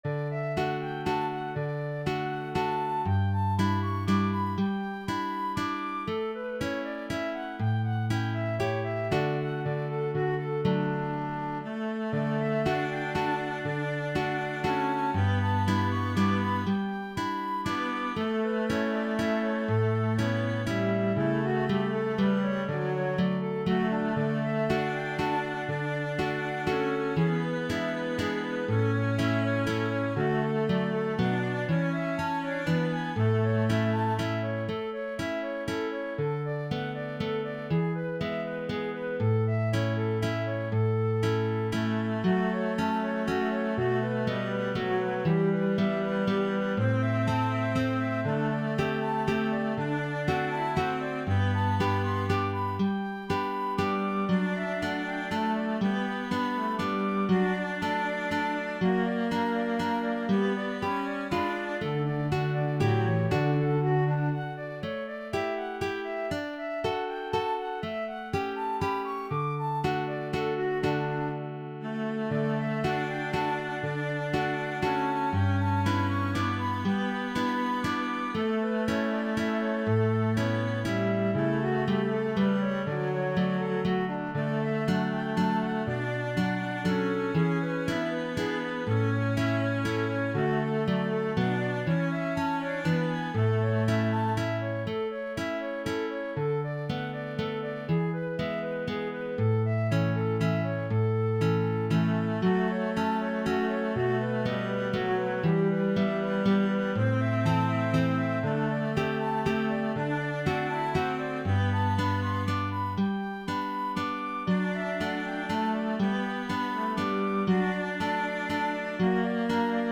Canzonetta from Don Giovanni Mozart Flute, cello, and guitar
Flute takes the mandolin part, cello takes the voice part.
A simple "oom-pah-pah" guitar part or piano part can replace the string orchestra.
Throughout, I've retained Mozart's delightful harmonization; in addition to standard triads, we find some surprising 6th, 7th, and diminished chords.
canzonetta-F-Vc-Gtr.mp3